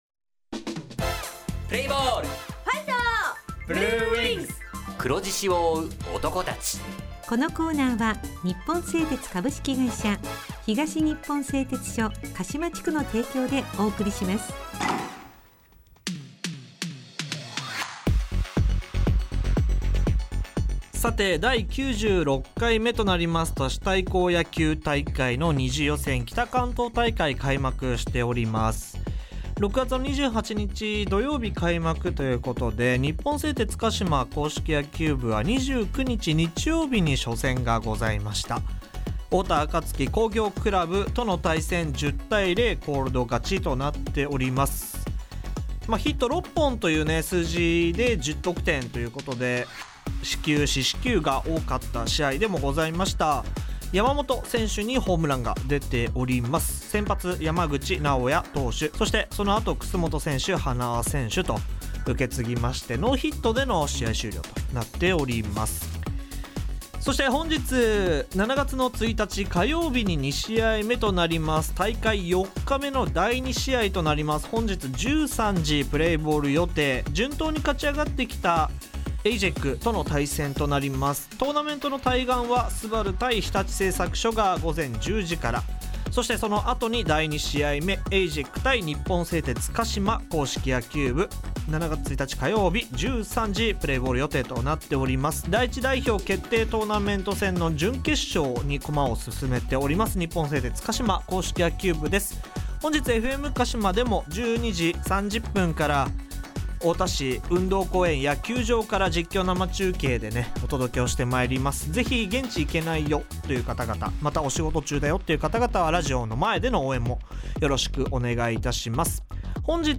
《選手インタビュー》
・電話インタビュー（３選手）